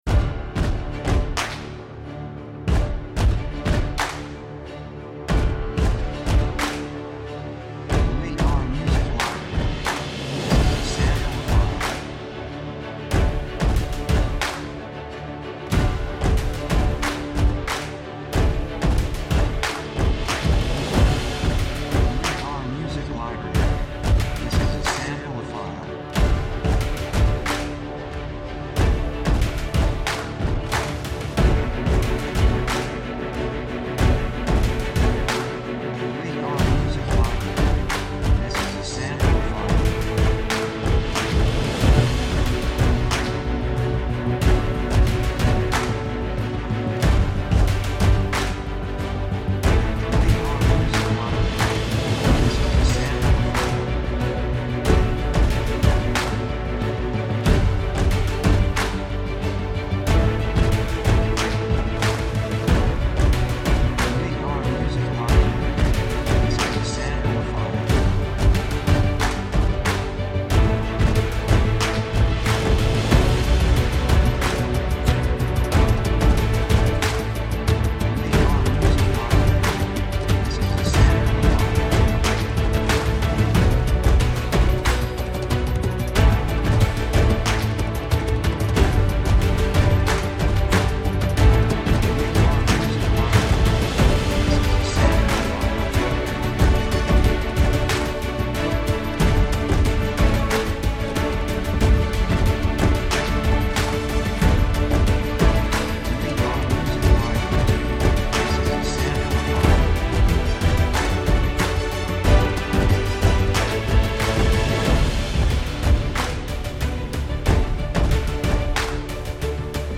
雰囲気高揚感, 決意, 喜び
曲調ポジティブ
楽器エレキギター, パーカッション, ストリングス, ボーカル, 手拍子
サブジャンルアクション, オーケストラハイブリッド
テンポミディアム